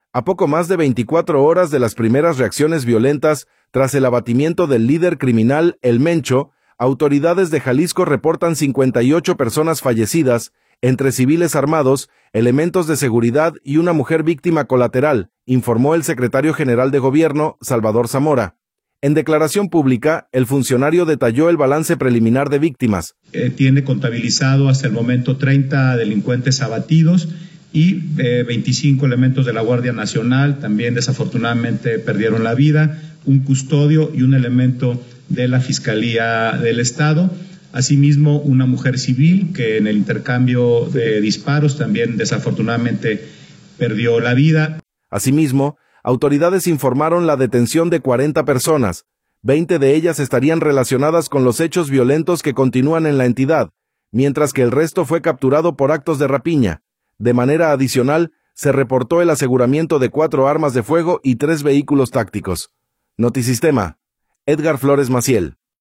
En declaración pública, el funcionario detalló el balance preliminar de víctimas: